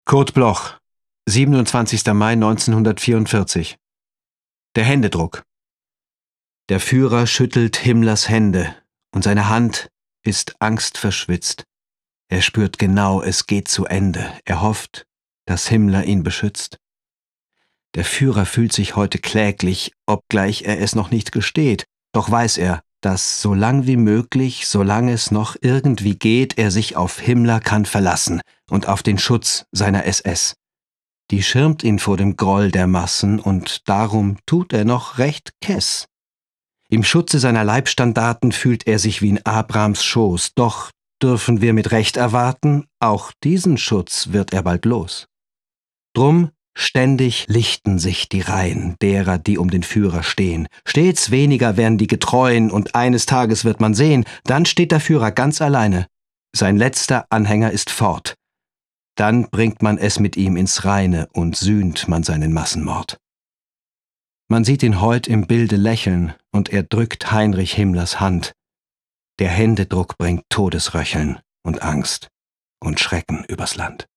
vorgetragen von Andreas Pietschmann